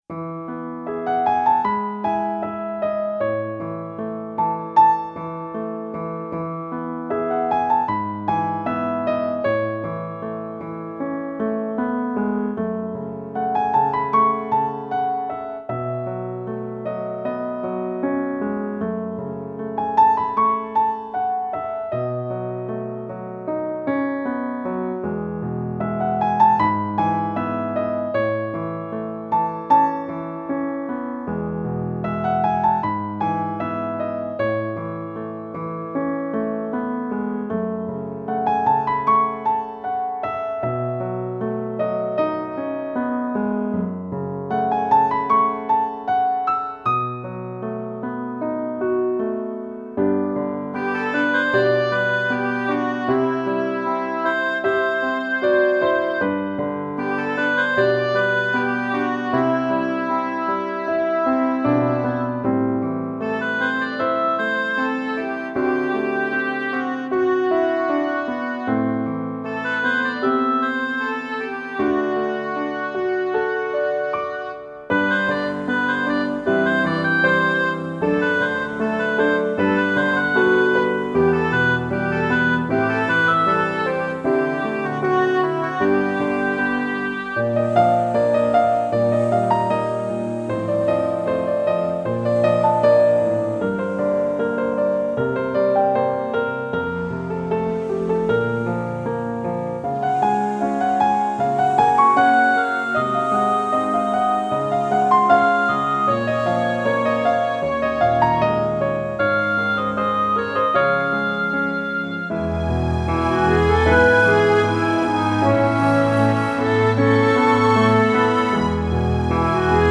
纯音雅乐
分类： 古典音乐、新世纪、纯音雅乐